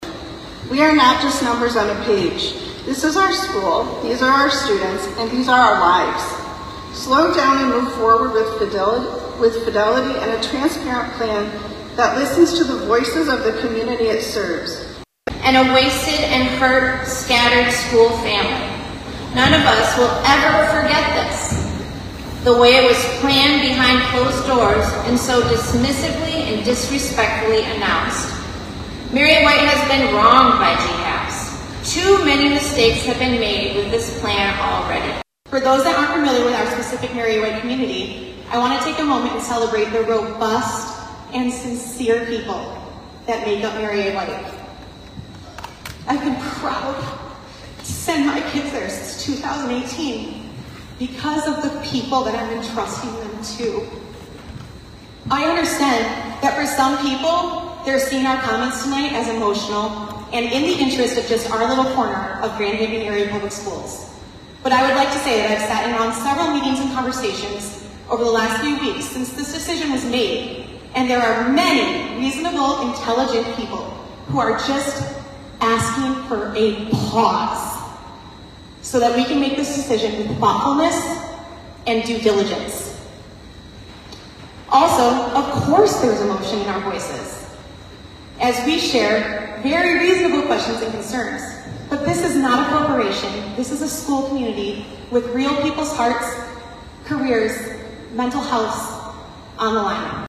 Grand Haven, MI – March 11, 2025 – During Monday night’s Grand Haven Area Public Schools (GHAPS) Board of Education meeting, the board voted 5-2 to approve the district’s recently announced plan to consolidate its seven elementary buildings into six and convert Mary A. White Elementary into an early childhood center.
Public comment lasted well into the evening.